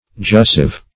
Search Result for " jussive" : The Collaborative International Dictionary of English v.0.48: jussive \jus"sive\, a. (Grammar) Indicating or expressive of a mild command; as, the jussive case.